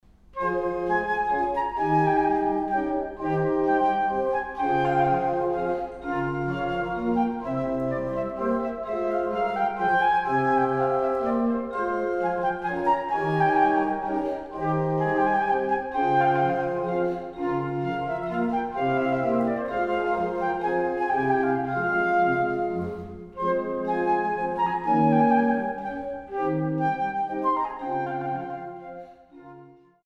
Kloß-Orgel der Kirche St. Magdalenen Langenbogen